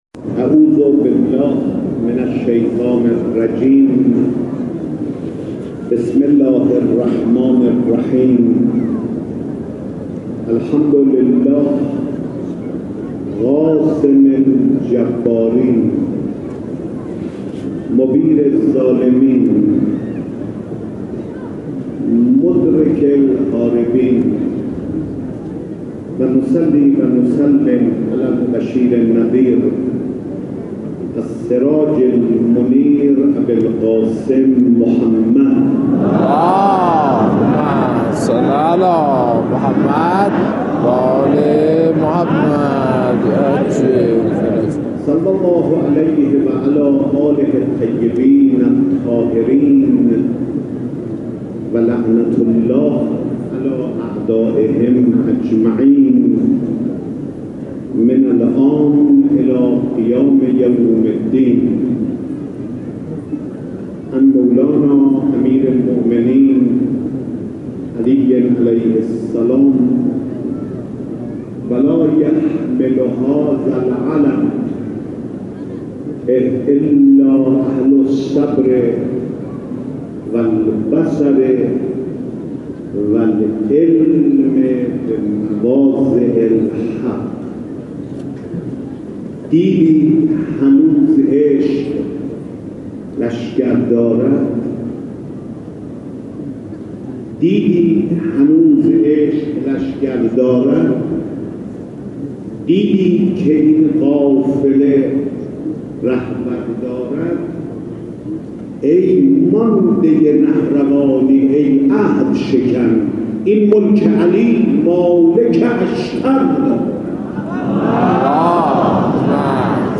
سخنرانی آیت الله خاتمی در روز 9 دی 93 با موضوع 7 نکته اساسی از فتنه 88